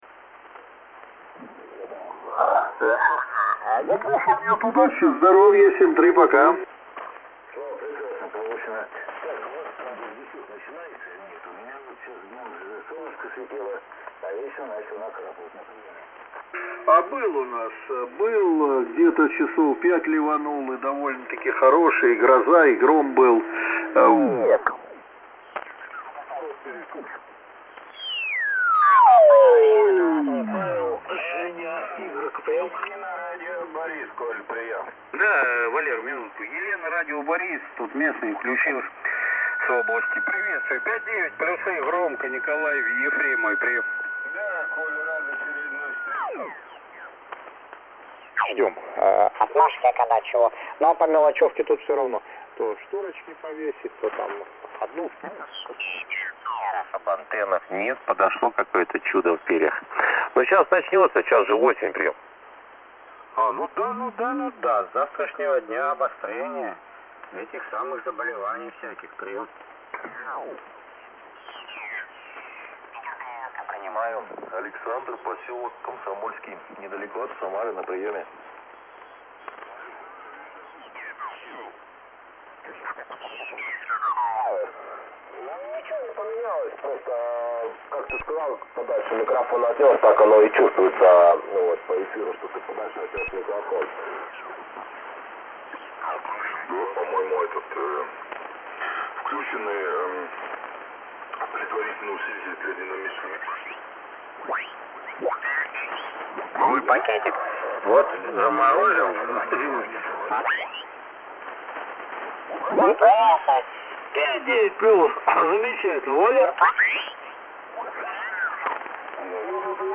При максимальной громкости в 100% явных искажений не слышно.
Вот запись эфира на 80 метрах: